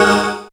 37hs01syn-g#.aif